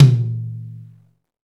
TOM XTOMMI0I.wav